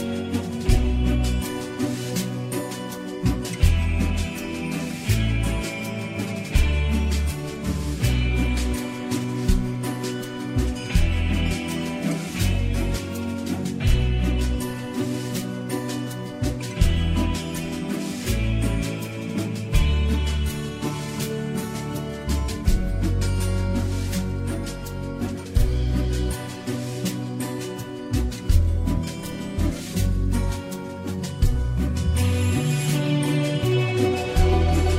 # Instrumental